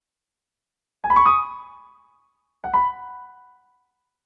スワイプの音